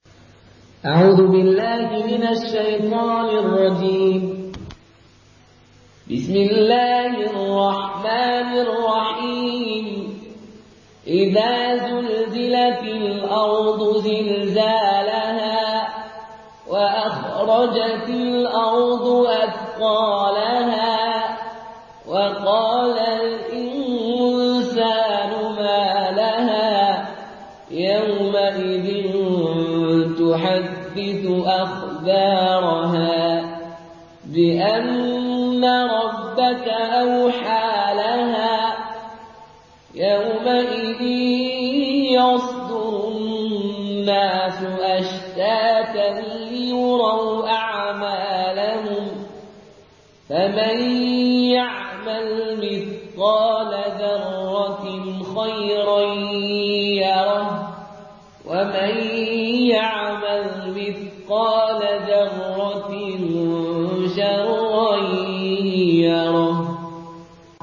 in قالون Narration
مرتل قالون عن نافع